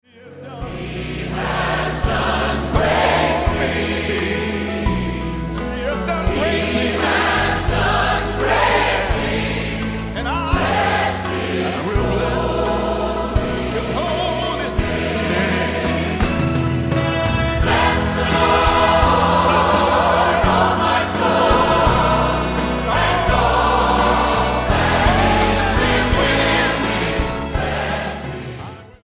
STYLE: Southern Gospel